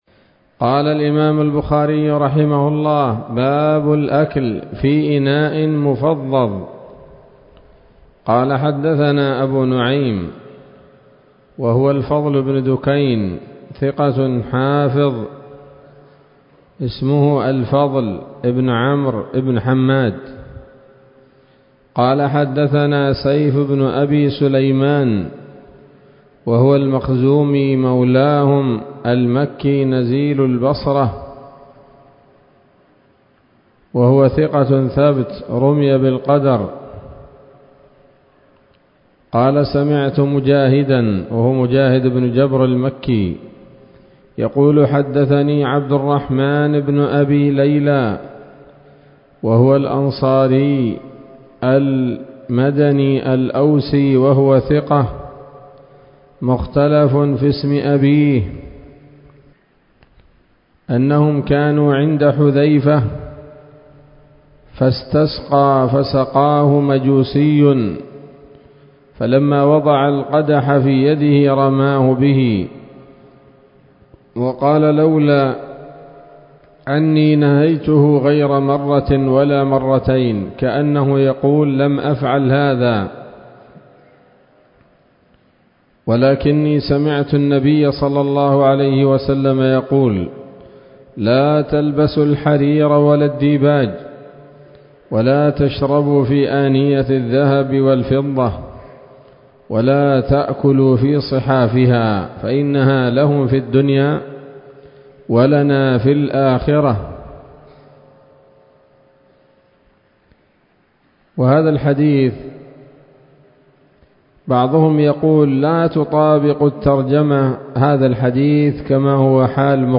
الدرس التاسع عشر من كتاب الأطعمة من صحيح الإمام البخاري